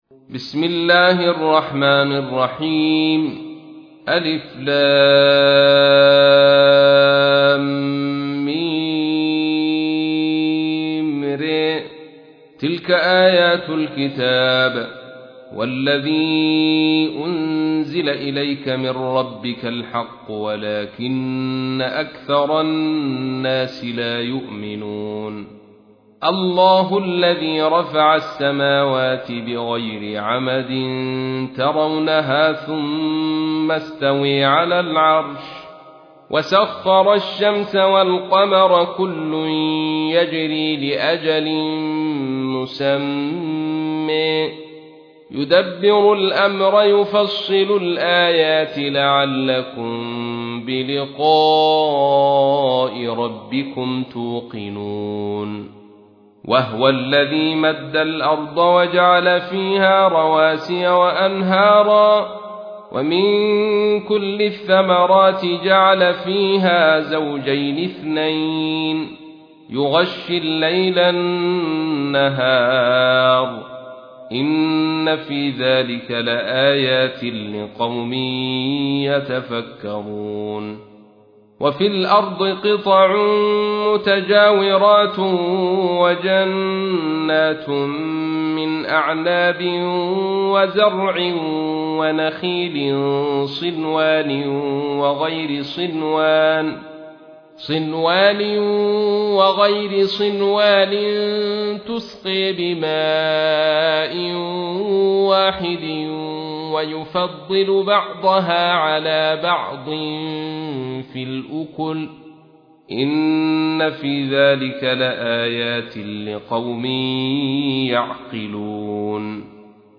تحميل : 13. سورة الرعد / القارئ عبد الرشيد صوفي / القرآن الكريم / موقع يا حسين